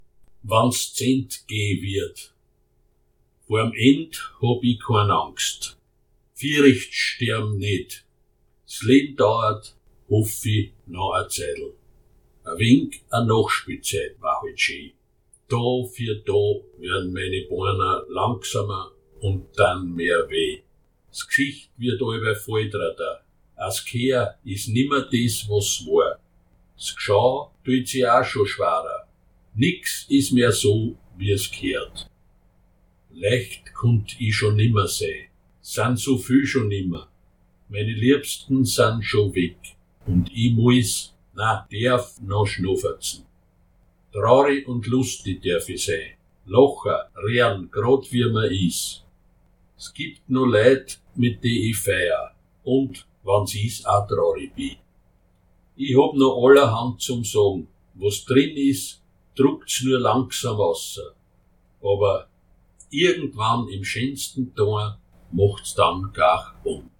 Gedichte in der Weinviertler Ui-Mundart